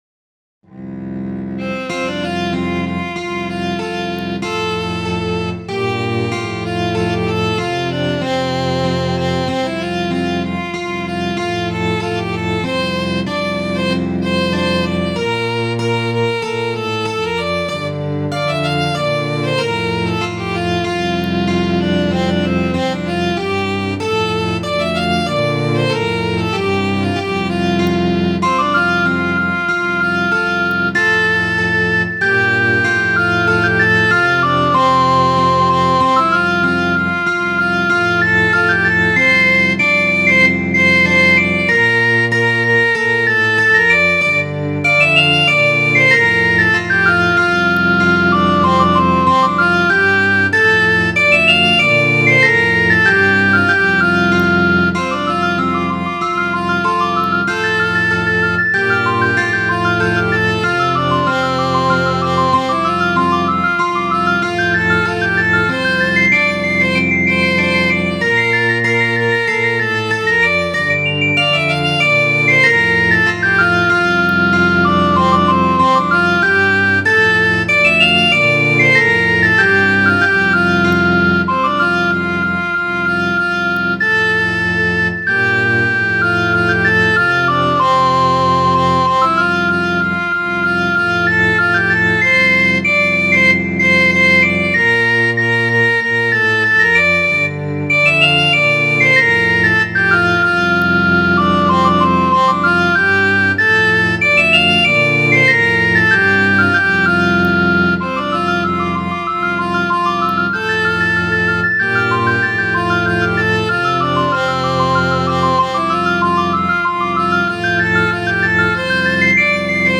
Midi File, Lyrics and Information to The Twa Sisters